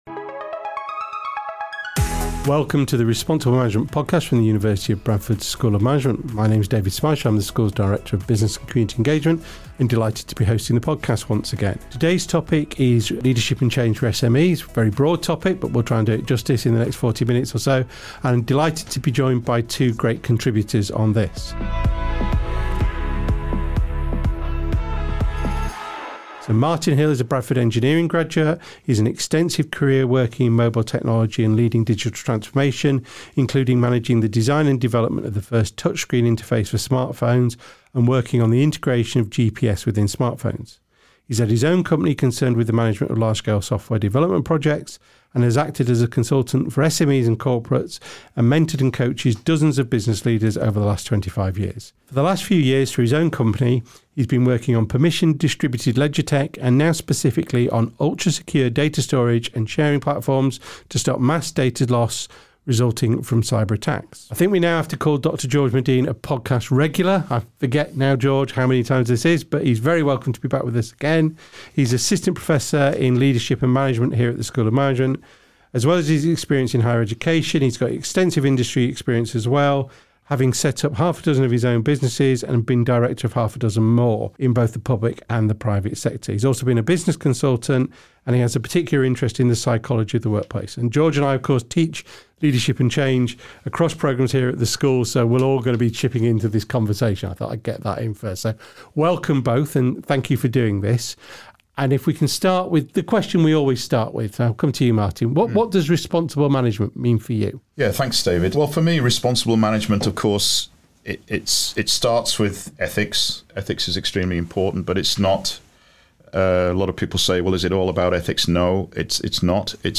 An interesting and dynamic conversation focuses on leadership through change and transition for SMEs and entrepreneurs as all three speakers bring experience and expertise to the conversation. Our guests explore the psychology of workforce, empathetic leadership and the impact of AI for leaders amongst much more.